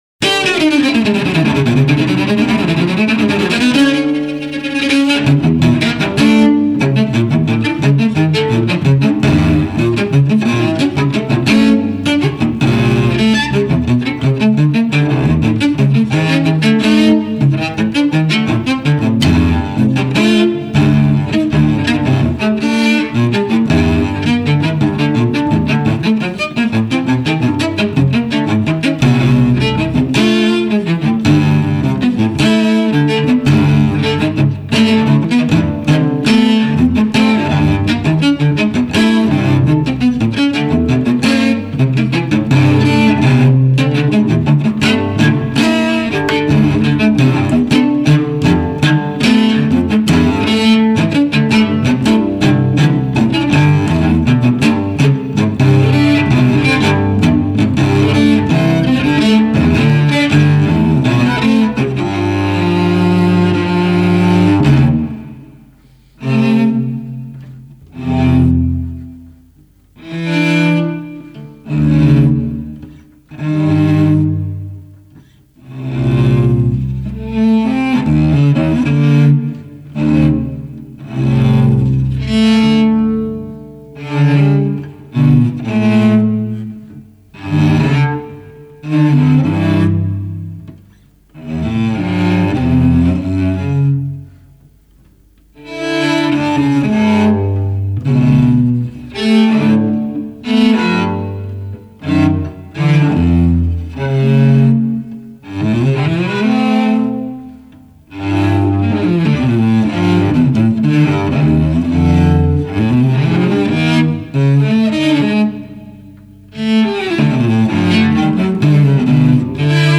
Douland (double air for cello).mp3